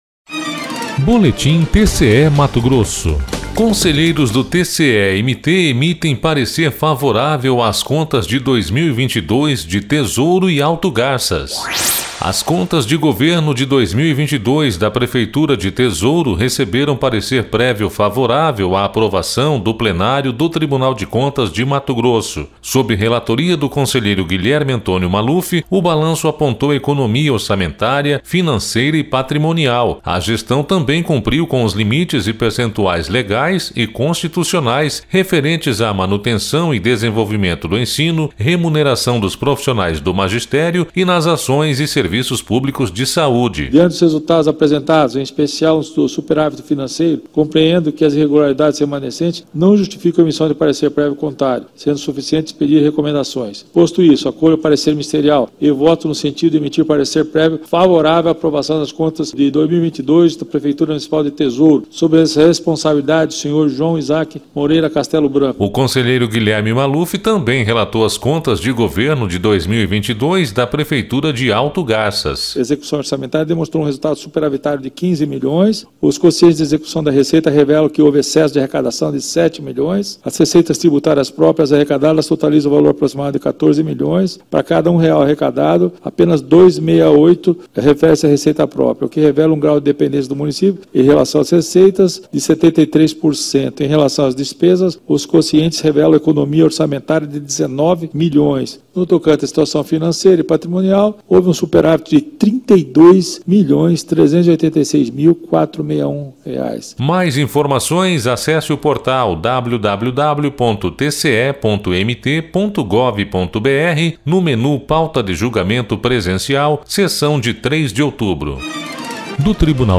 Sonora: Guilherme Antonio Maluf - conselheiro do TCE-MT